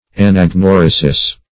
Search Result for " anagnorisis" : The Collaborative International Dictionary of English v.0.48: Anagnorisis \An`ag*nor"i*sis\, n. [Latinized fr. Gr.